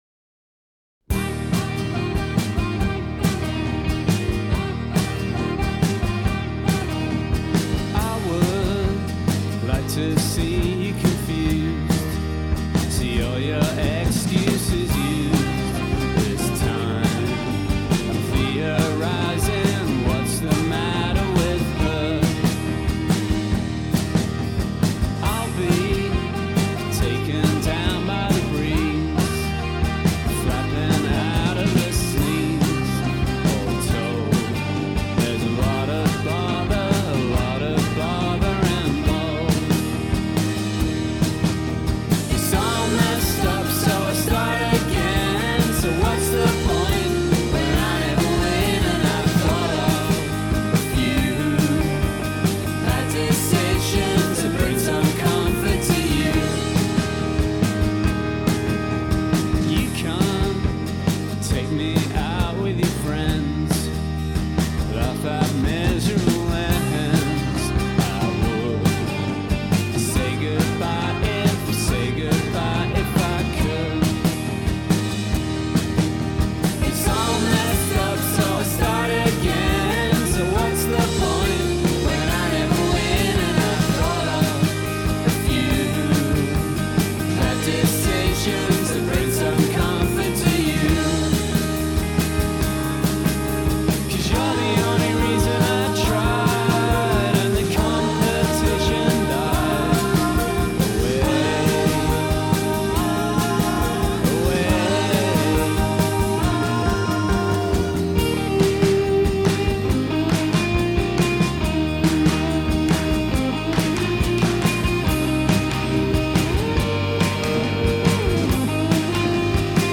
riusciva a coniugare stile british